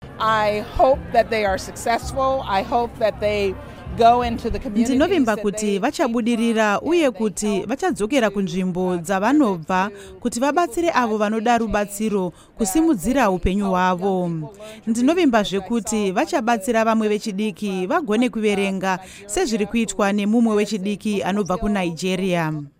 Hurukuro naAmai Linda Thomas-Greenfield